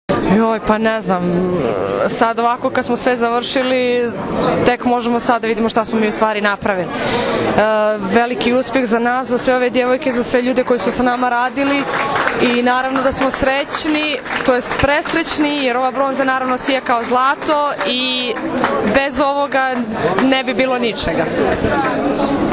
Odbojkašice su zatim produžile u beogradski hotel „M“, gde im je priređen svečani doček.
IZJAVA SILVIJE POPOVIĆ